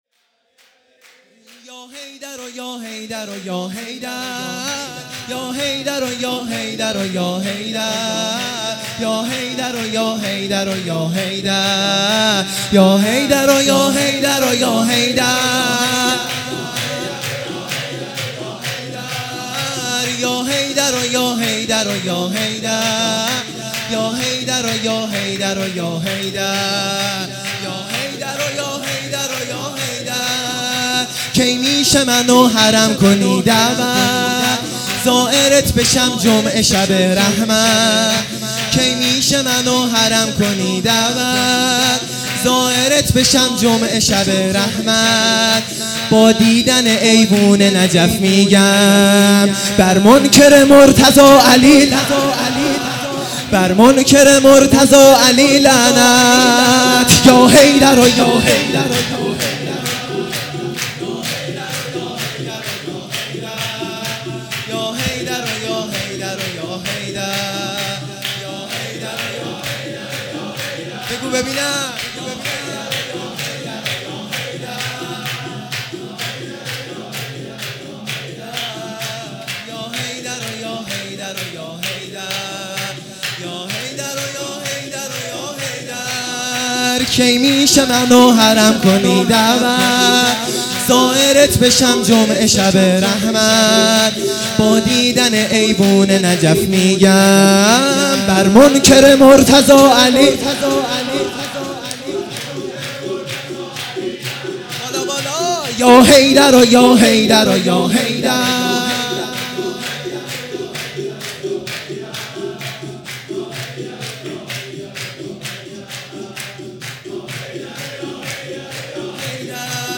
عید غدیر خم